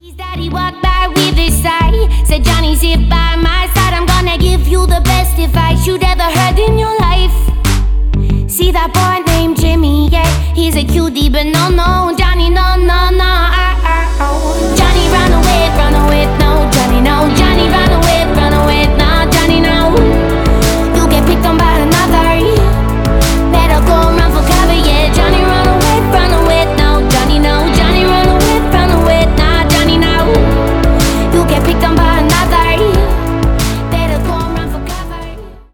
Поп Музыка
тихие